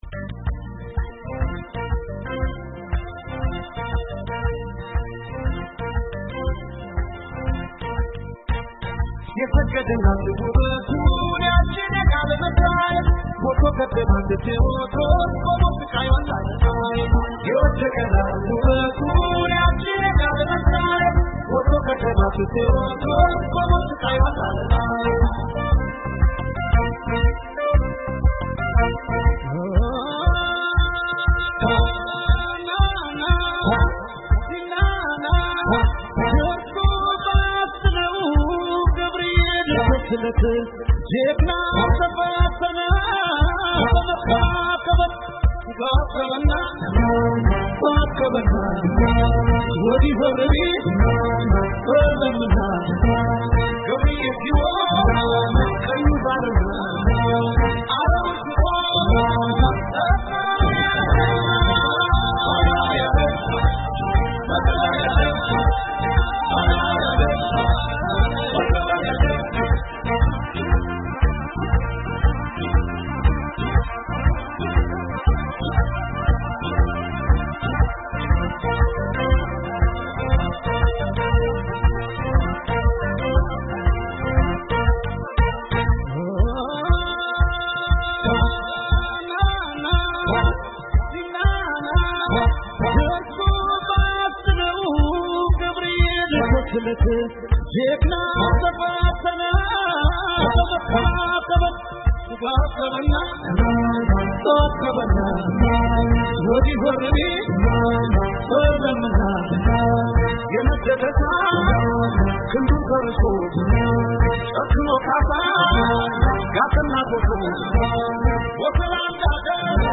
ቴዲ አፍሮ ቀጠሮ ለተያዘለት ቃለ ምልልስ በራዲዮ መጽሔት የ‘ጥበብ እና የጥበበኛው መስኮት’ ብቅ ብሎ ከአሜሪካ ድምጽ ስቱዲዮ እንገናኝ ይላል።